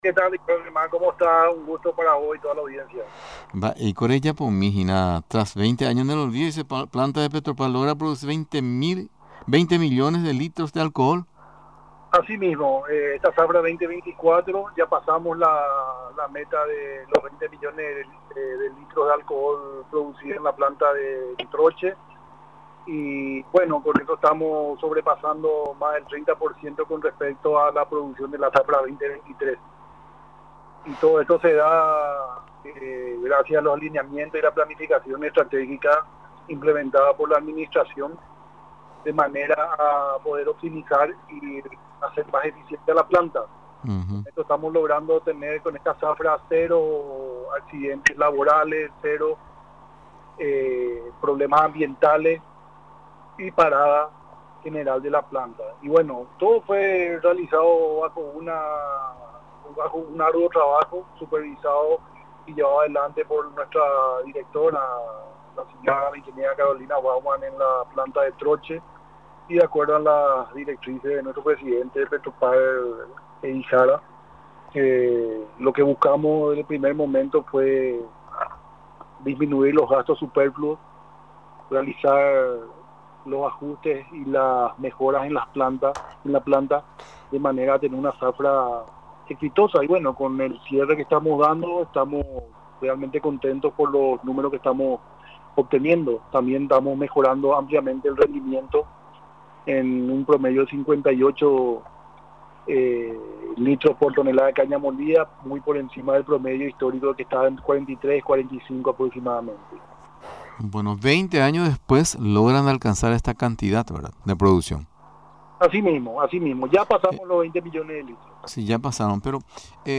Durante la entreviste en Radio Nacional del Paraguay, explicó todos los pormenores de la tarea realizada para levantar la planta de Troche.